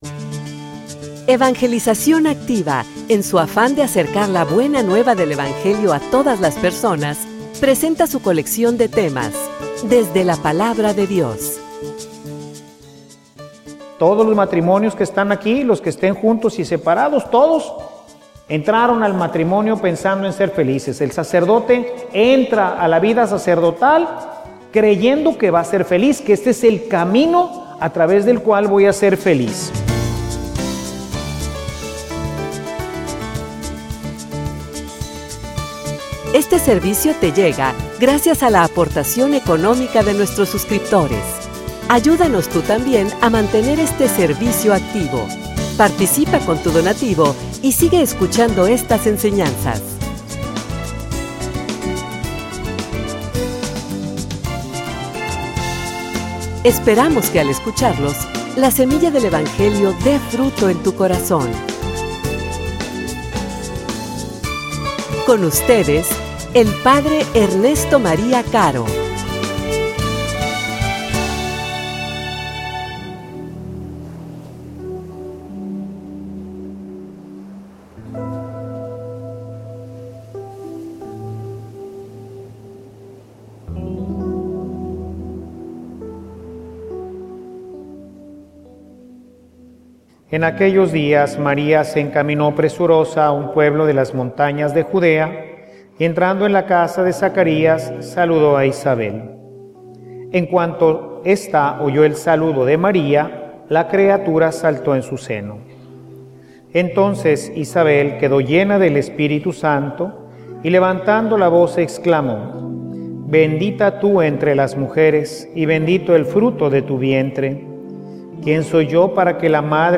homilia_fe_que_se_hace_caridad.mp3